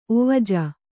♪ ovaja